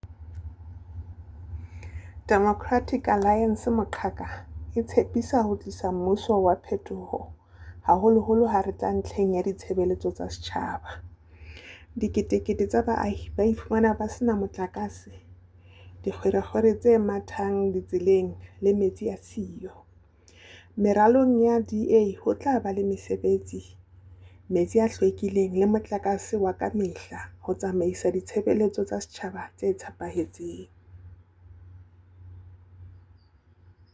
Sesotho soundbites by Cllr Palesa Mpele and